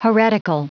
Prononciation du mot heretical en anglais (fichier audio)